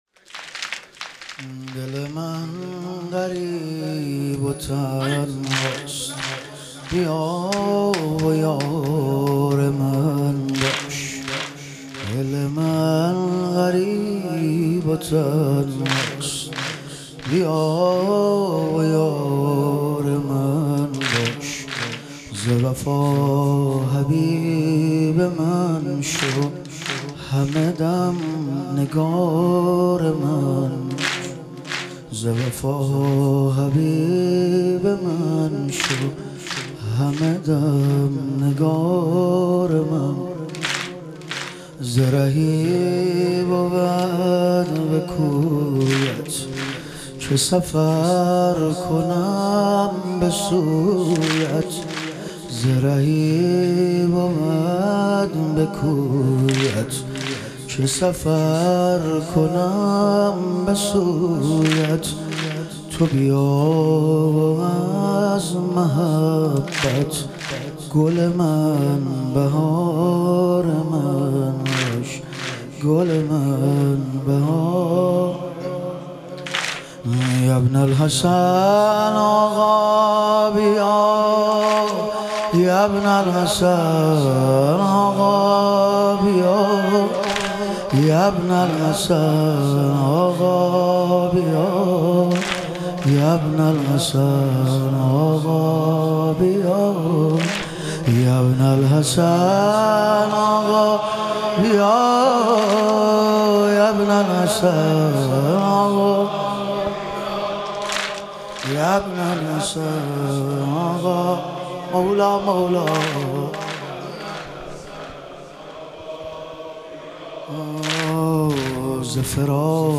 شهادت حضرت جعفرطیار علیه السلام - واحد